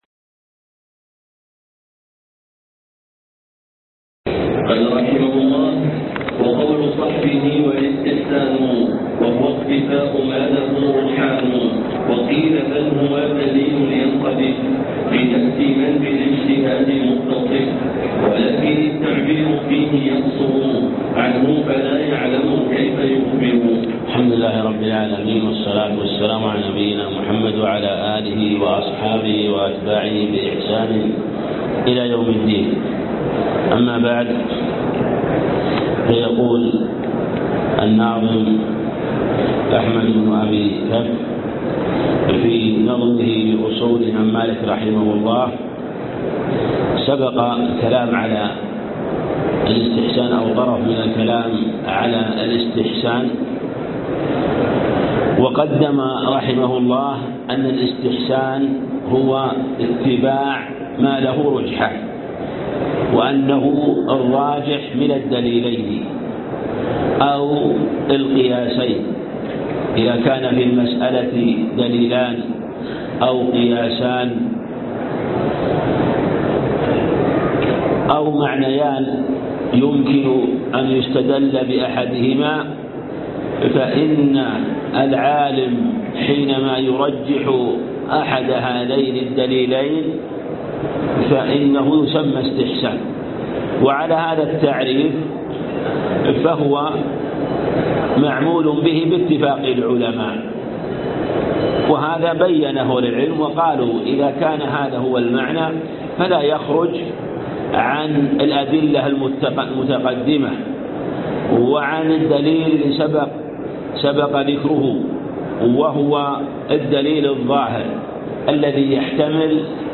الدرس( 1)من قوله وقول صحبه والاستحسان -منظومة أحمد بن أبي كفّ في أصول الإمام مالك